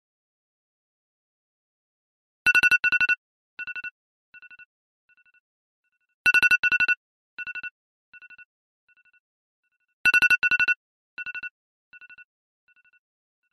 Iphone Alarm Radar